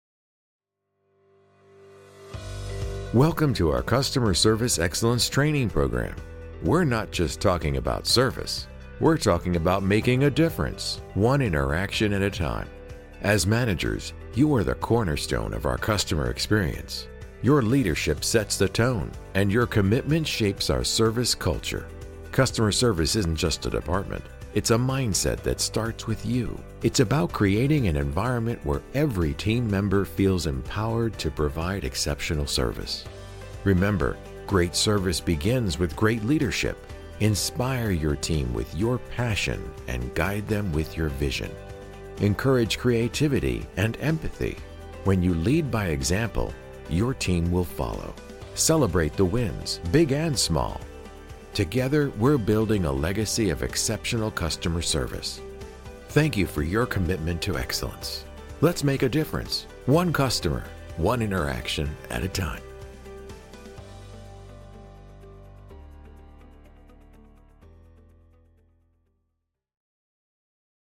Professional Voice Artist
Customer Service TRAINING
English - USA and Canada
Middle Aged
Senior